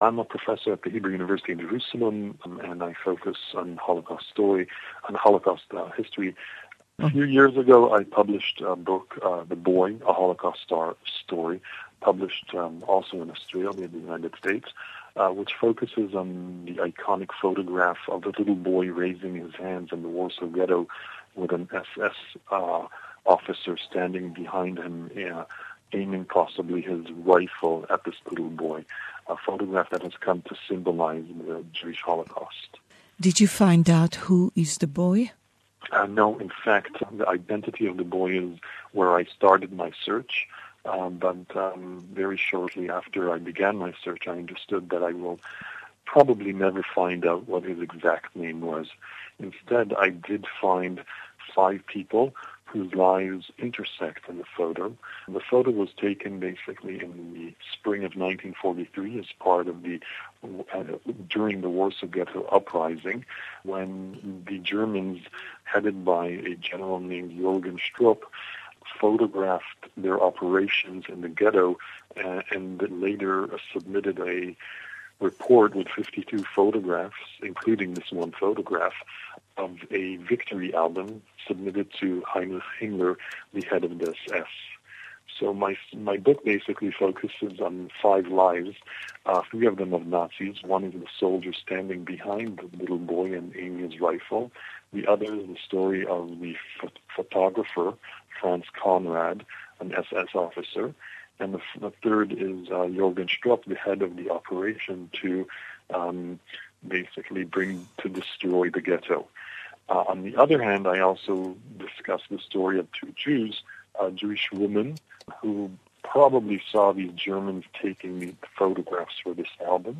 An Interview in English